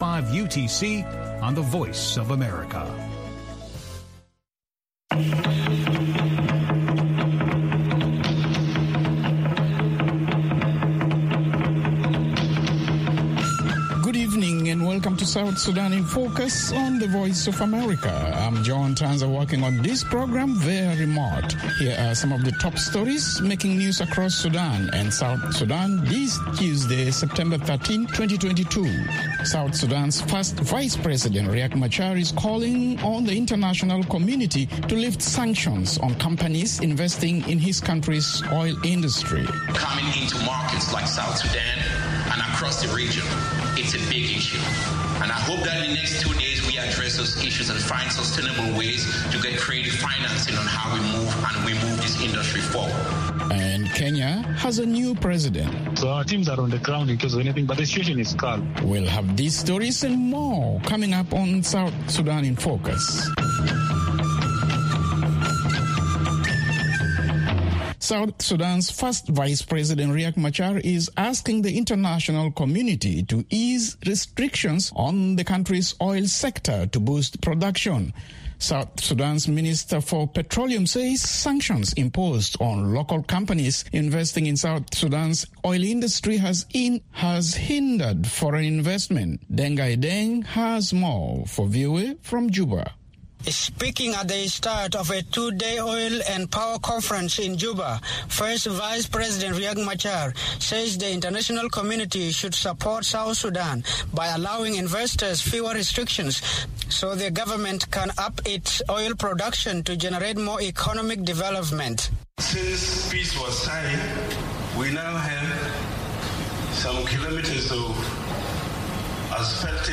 and a network of reporters around South Sudan and in Washington.